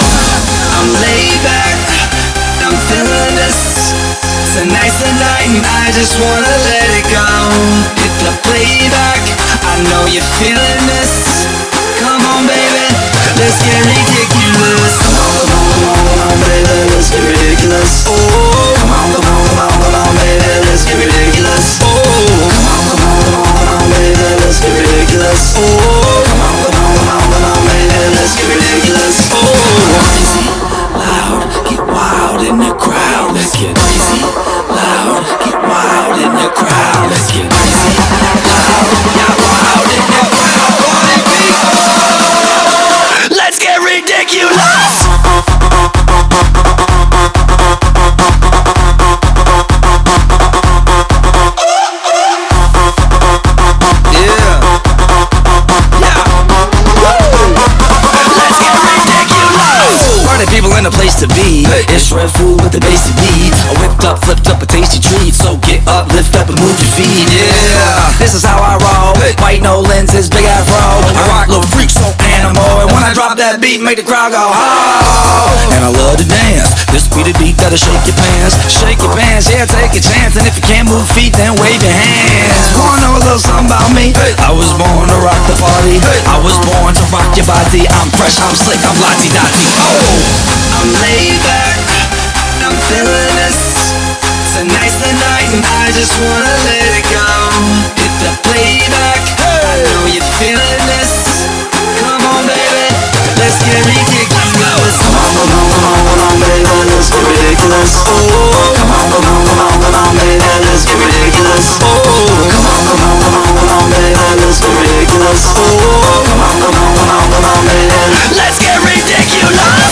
BPM128
MP3 QualityMusic Cut